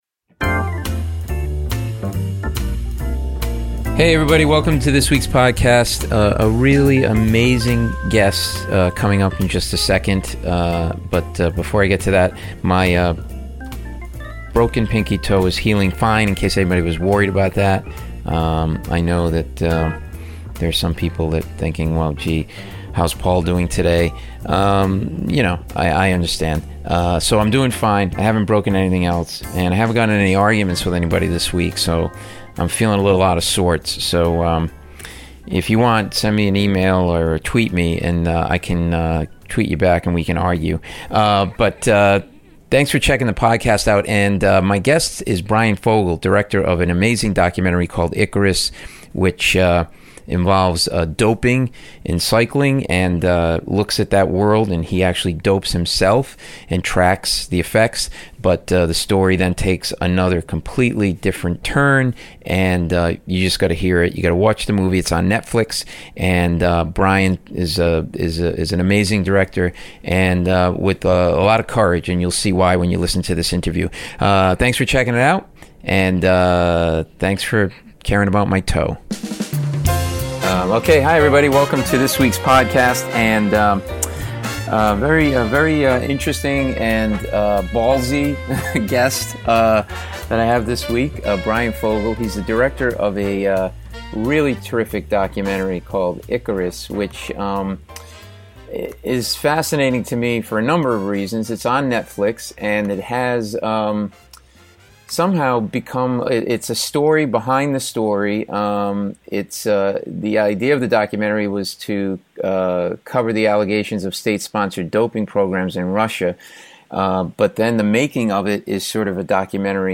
Bryan Fogel - Director of "ICARUS" on Netflix (Paul Mecurio interviews Bryan Fogel; 21 Aug 2017) | Padverb